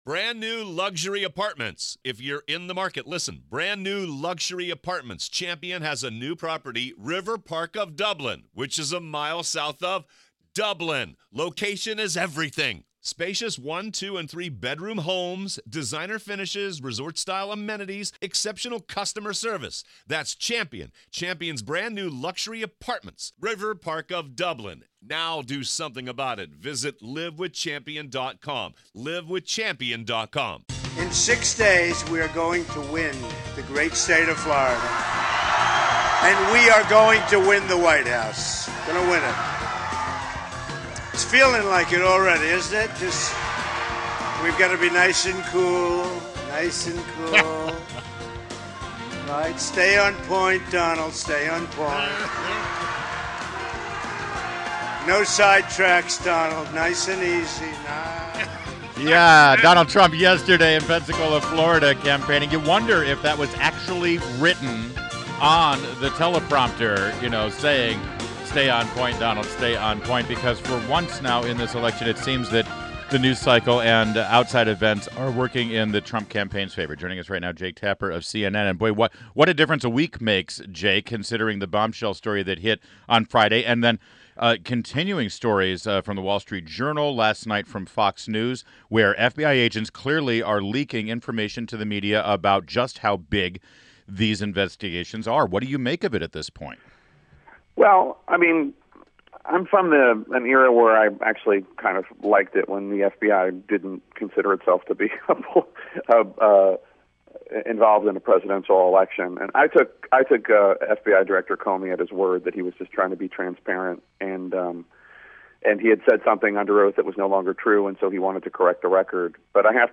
INTERVIEW — JAKE TAPPER – Anchor of THE LEAD and STATE OF THE UNION on CNN – discussed the state of the election.